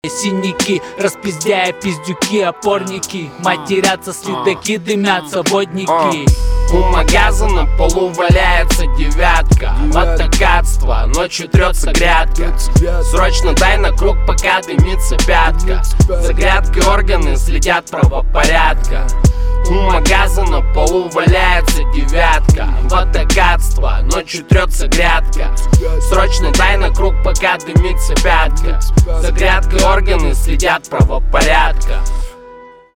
русский рэп , битовые , басы
жесткие